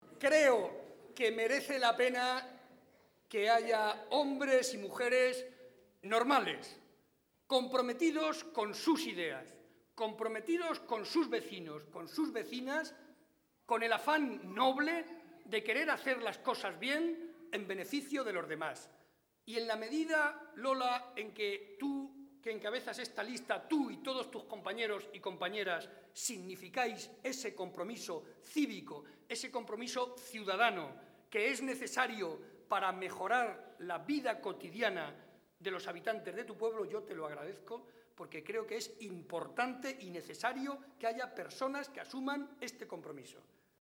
Momento del acto público del PSOE celebrado en Alovera.